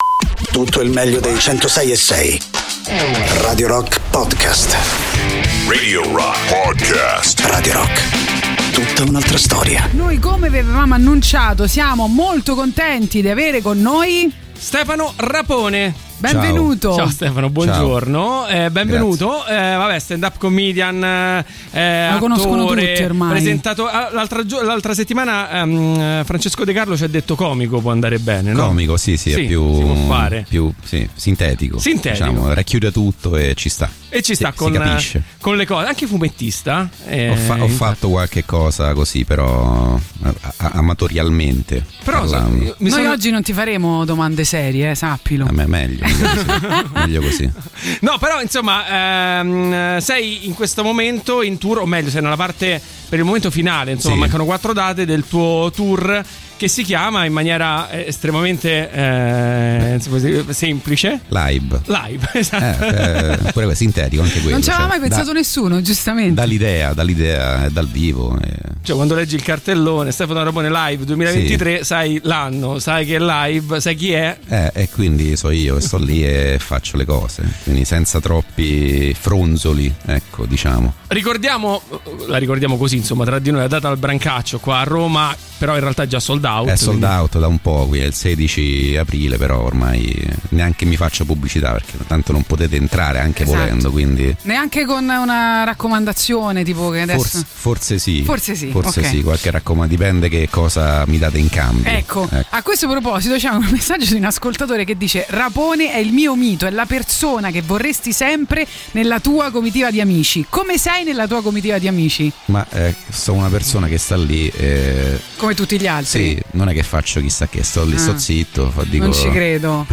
Interviste: Stefano Rapone (06-04-23)
ospite in studio